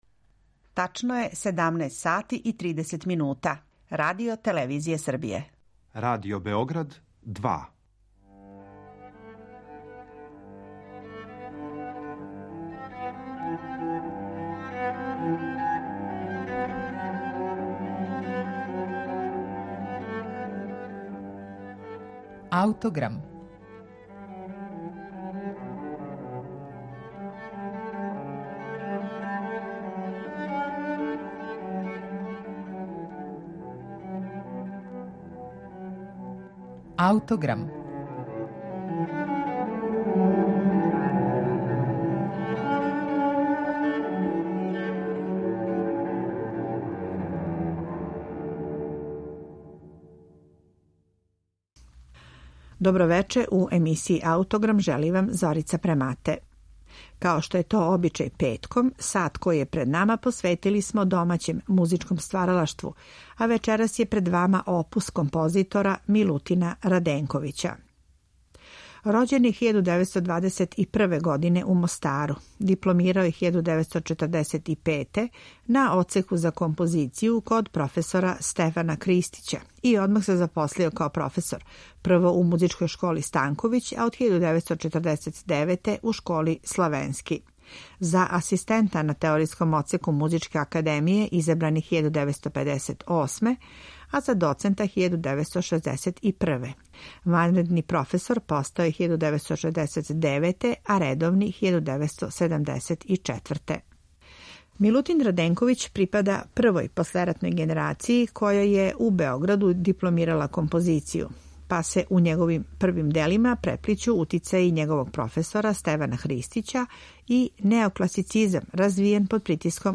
Прелиди су настали 1951.године, као технички сложене минијатуре у којима је аутор примењивао своје велико знање традиционалне хармоније и проширеног тоналитета. Емитујемо архивски снимак из 1963. године